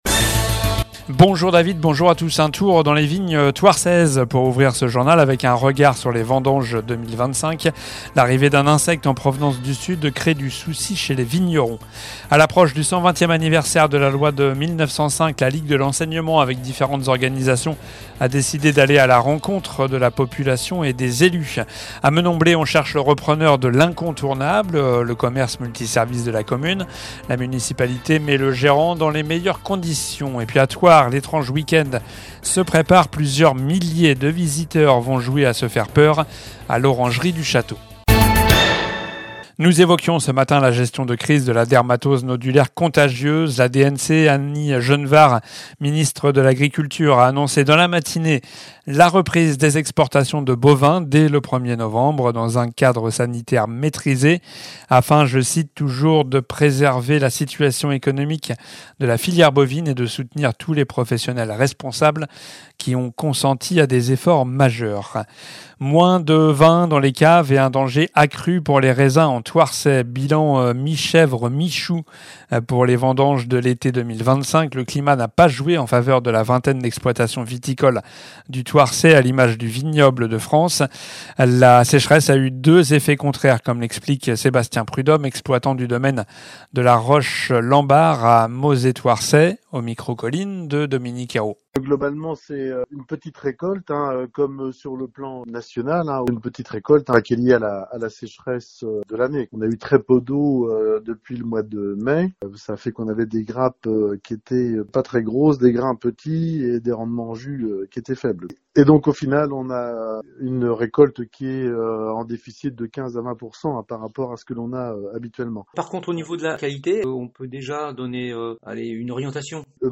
L'info près de chez vous